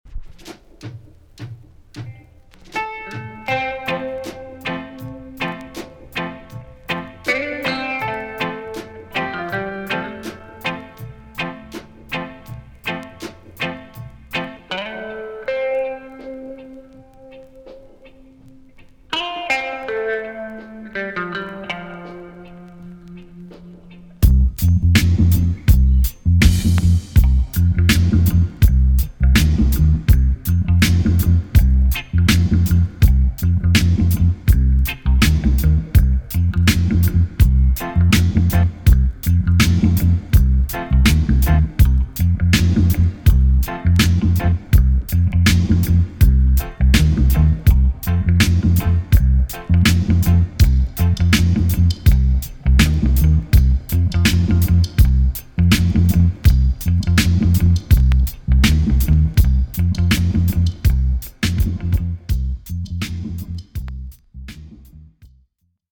TOP >DISCO45 >VINTAGE , OLDIES , REGGAE
B.SIDE Version
EX- 音はキレイです。